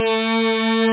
tb303-samples-1
1 channel
303-analog.mp3